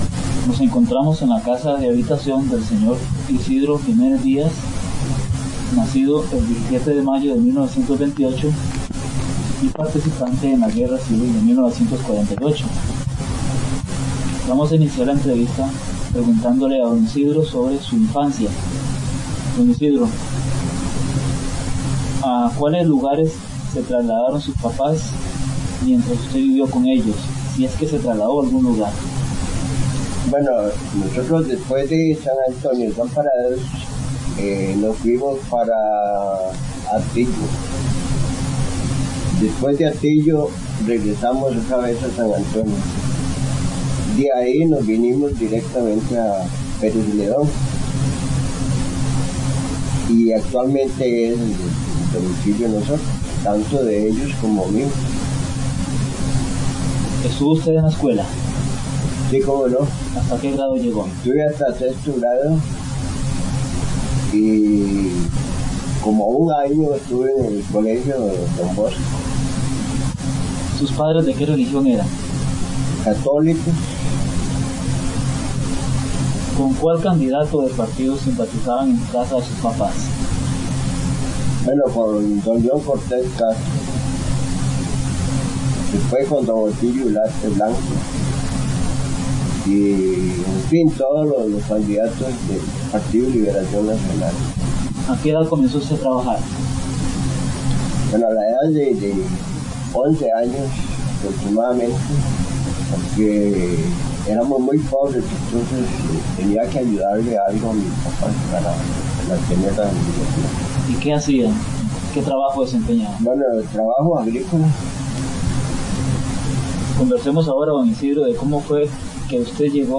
Entrevista
Notas: Casete de audio y digital